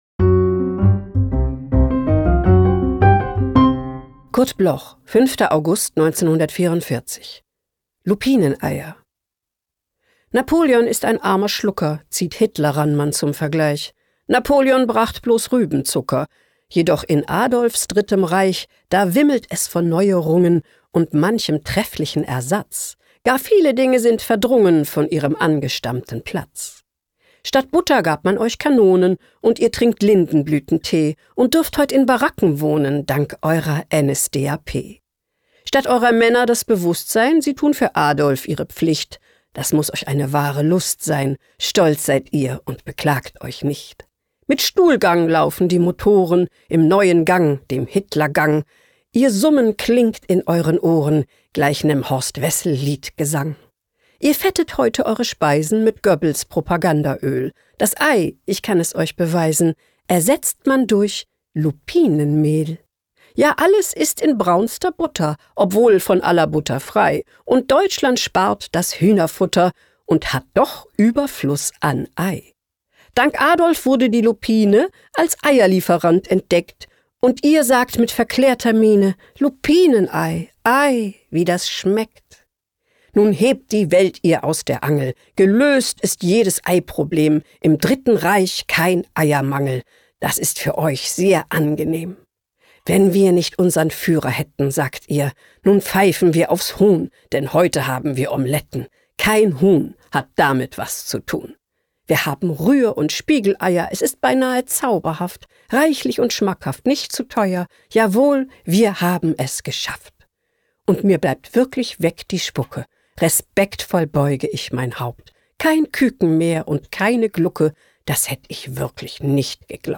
Johanna-Gastdorf-Lupineneier-mit-Musik_raw.mp3